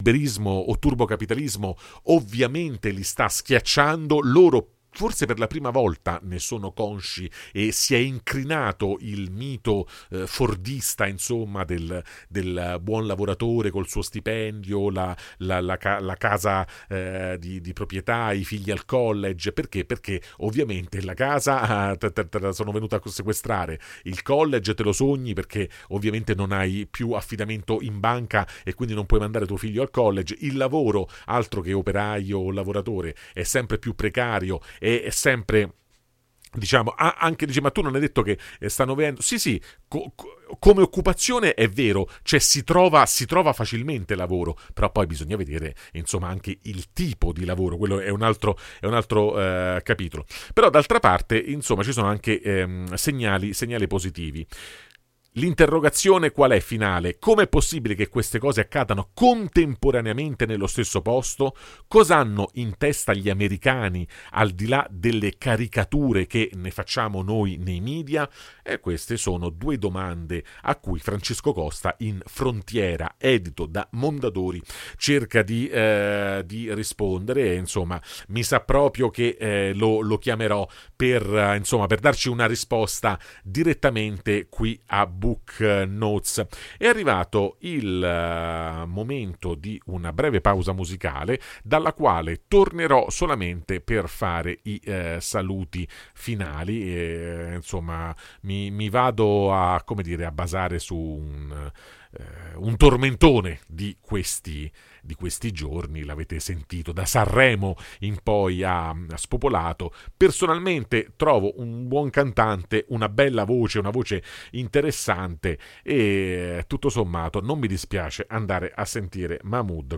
Ne abbiamo parlato con il giornalista
In studio